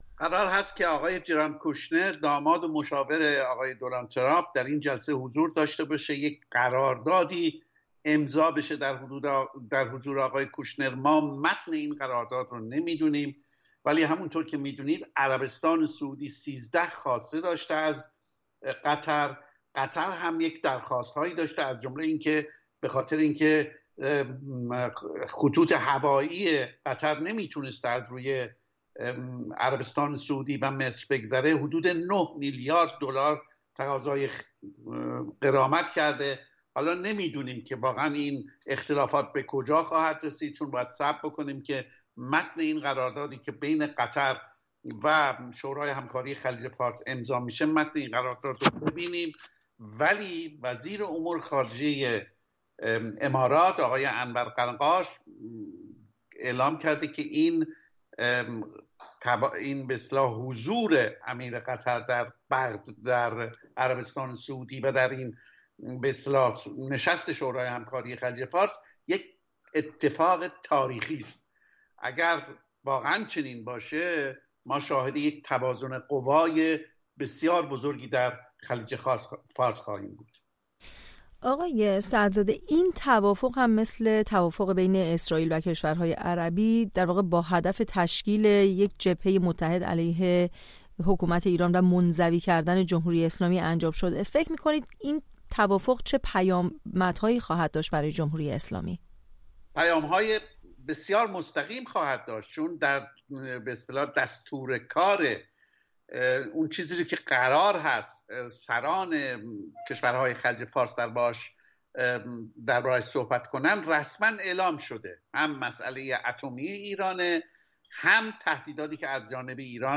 گفت و گویی